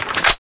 shoot_net2.wav